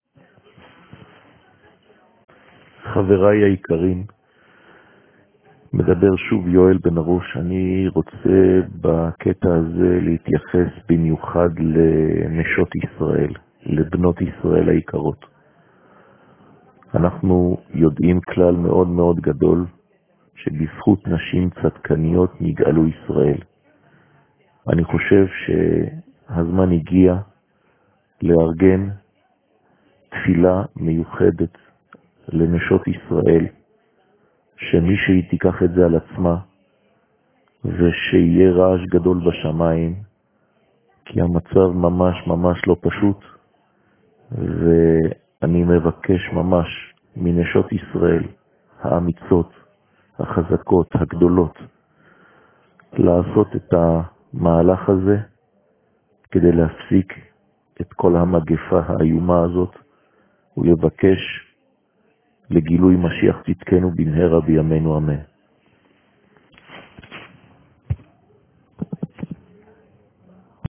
שיעור מ 23 מרץ 2020